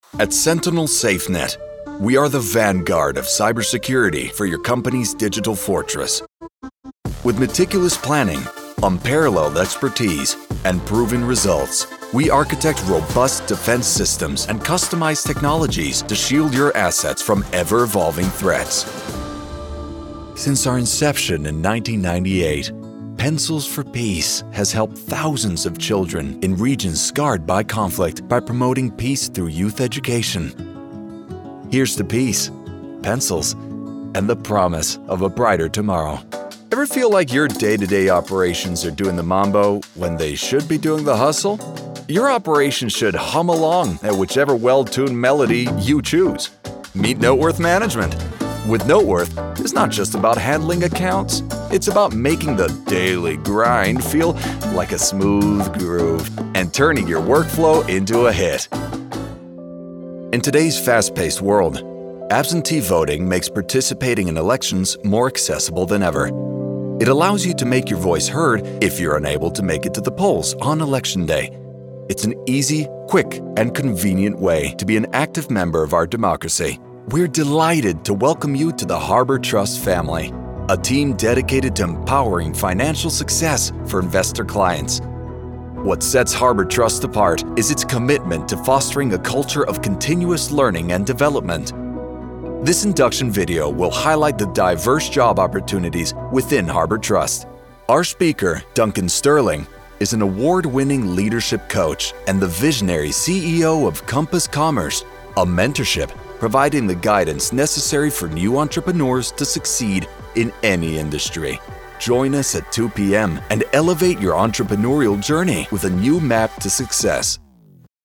Corporate Reel
General American, Spanish, Southern USA, New Orleans
Young Adult